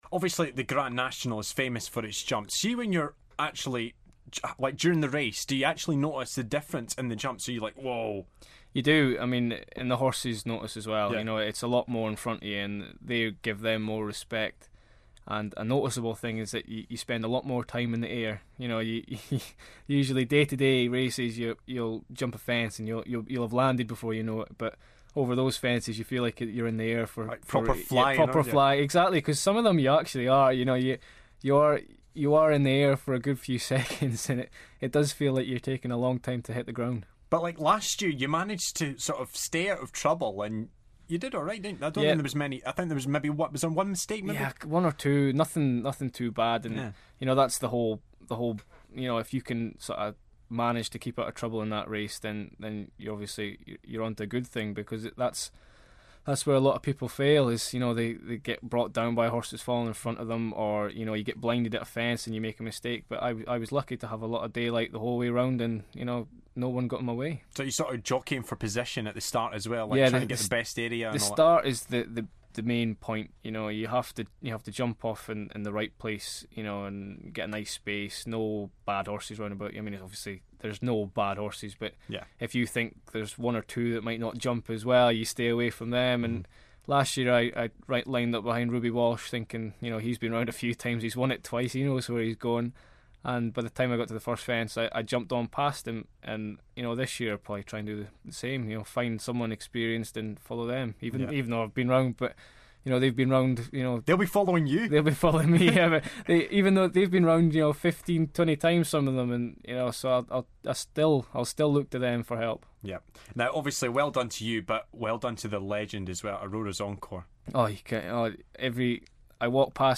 interviews Ryan Mania ahead of the Grand National 2014 - part 3